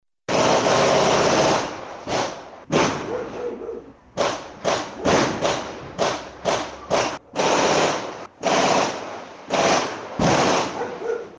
Ráfagas de disparos
Los vecinos de Playa Ancha lograron captar un registro en audio de lo que vivieron durante el pasado fin de semana, y que acusan que ocurre habitualmente. Se trata de una ráfaga de disparos a poca distancia de sus inmuebles.